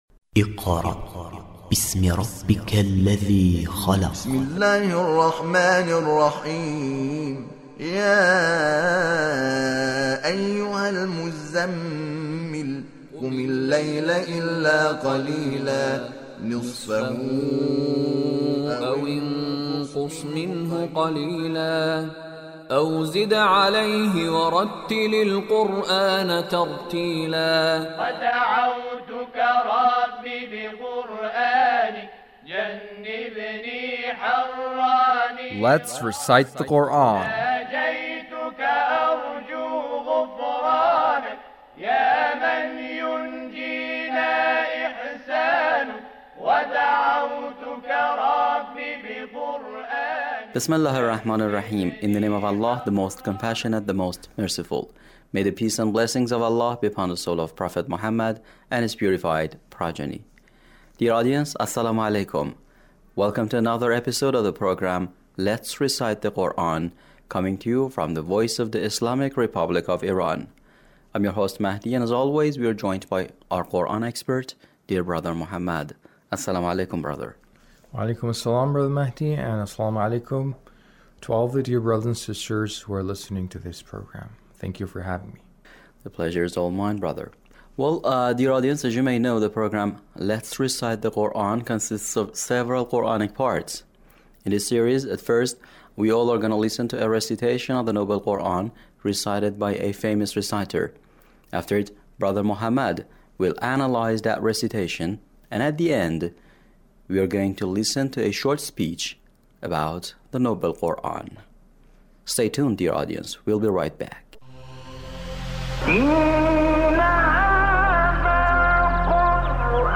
Recitation of Sheikh Shaban Sayyad